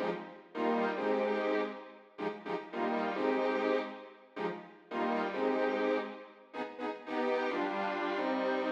13 Strings PT 1-2.wav